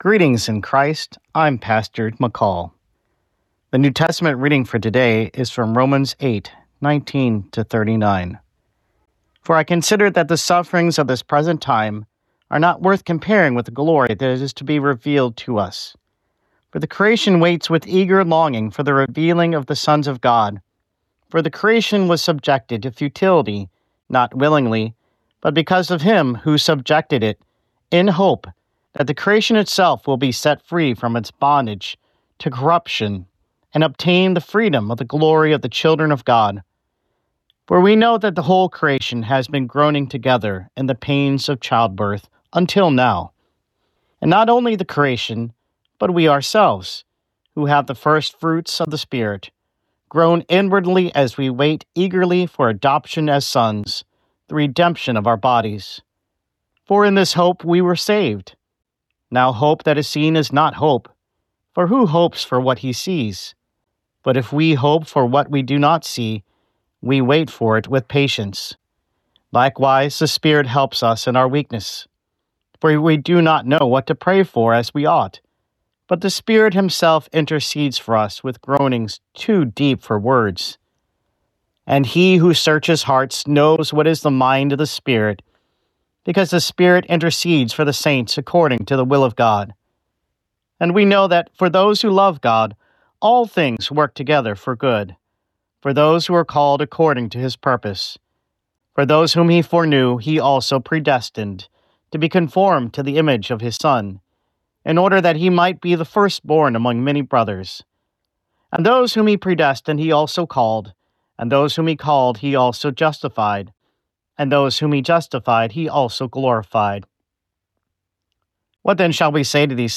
Morning Prayer Sermonette: Romans 8:18-39
Hear a guest pastor give a short sermonette based on the day’s Daily Lectionary New Testament text during Morning and Evening Prayer.